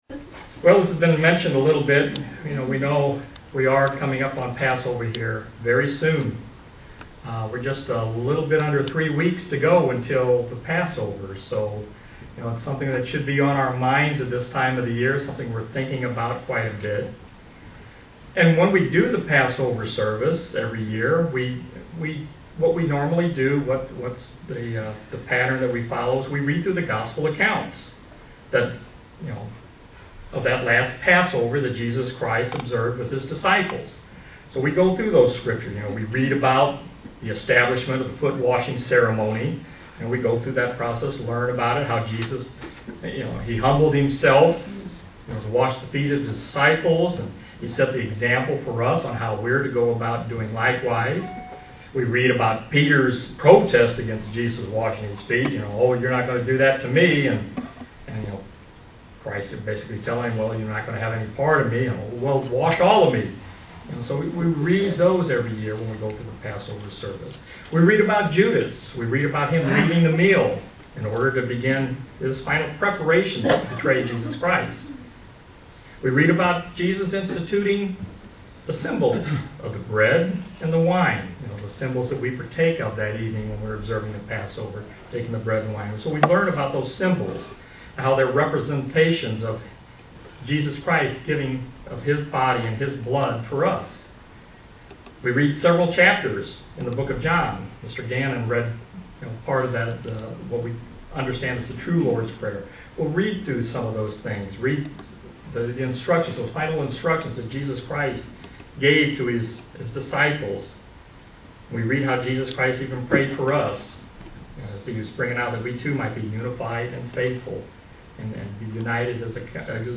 Why did Christ have to suffer as He did? This sermon will look at the events following the Passover meal on the day of Christ's crucifixion.